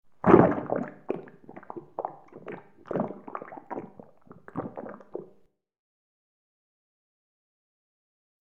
Liquid Glugs; Interior Thick Slosh And Gurgles Reverberant